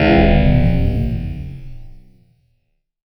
SCIFI_Down_08_mono.wav